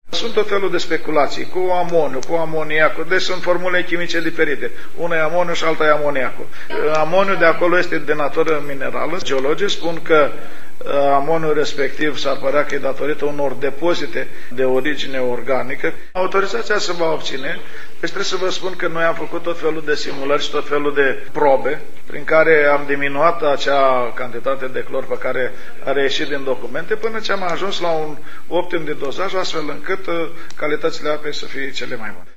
Dupa 3 saptamani de concediu, edilul sef a sustinut o conferinta de presa in care a cautat sa lamureasca toate nedumeririle jurnalistilor referitoar la calitatea apei din sursa proprie a orasului.
Mai nou, spune Gabi Ionascu, geologii apreciaza ca amoniu din apa este de natura minerala: